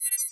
UI SCI-FI Tone Bright Dry 02 (stereo).wav